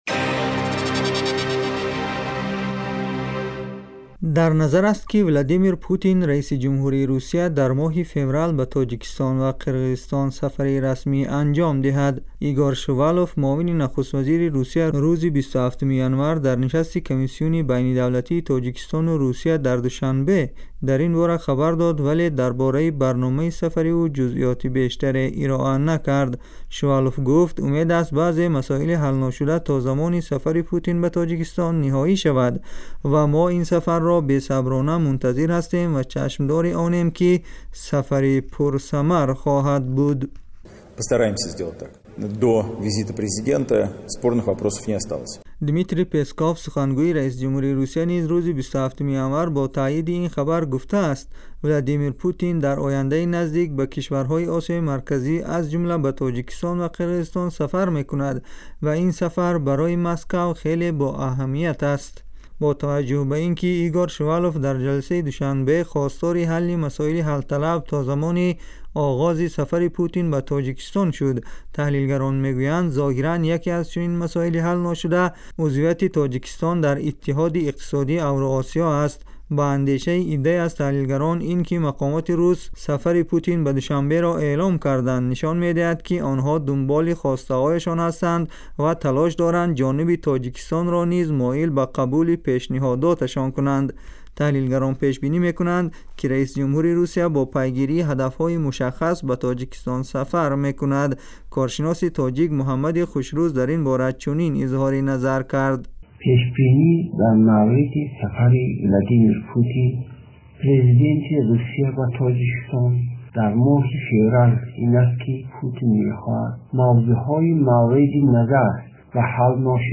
гузориши вижа